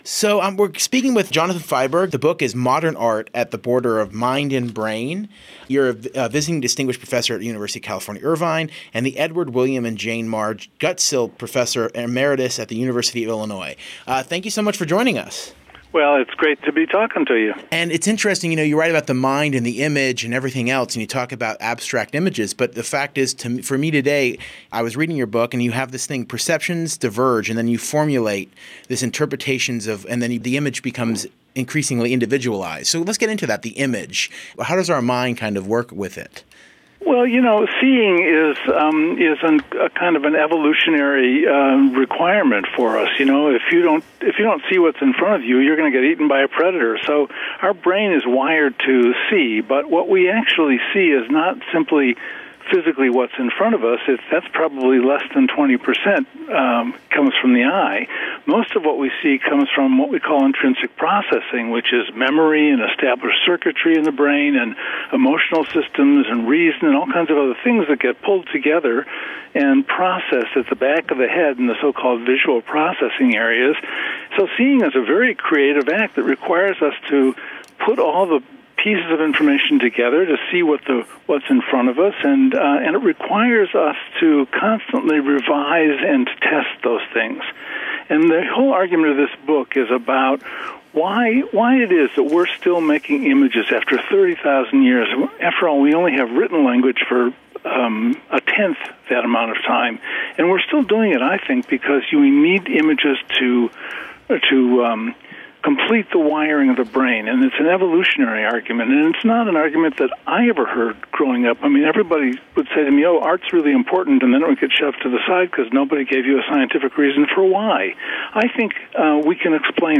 KPFK Pacifica Radio Los Angeles interview about Modern Art at the Border of Mind and Brain